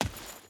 SFX / Footsteps / Dirt / Dirt Chain Run 5.ogg
Dirt Chain Run 5.ogg